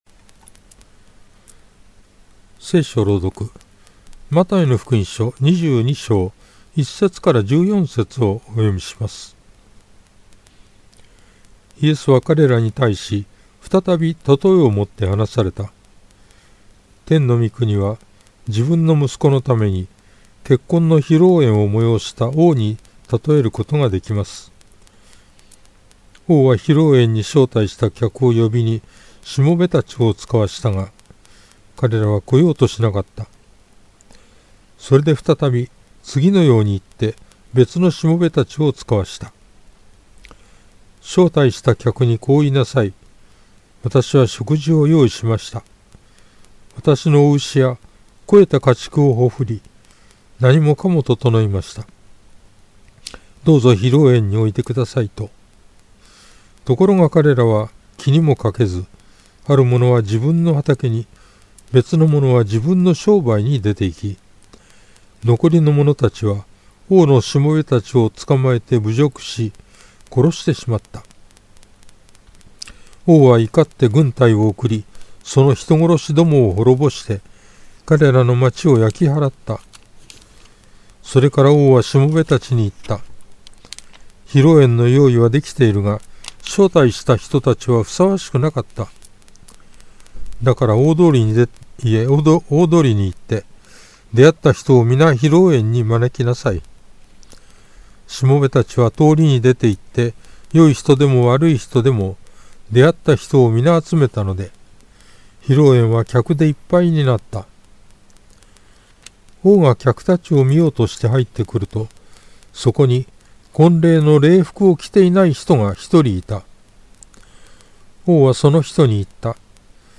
BibleReading_Math22.1-14.mp3